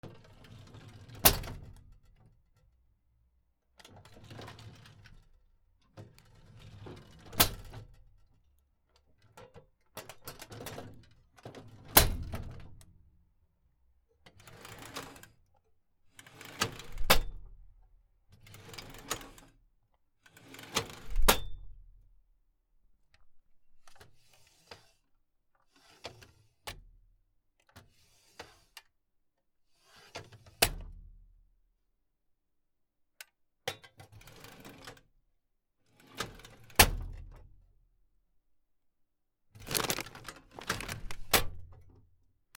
引き出し スチール オフィスデスク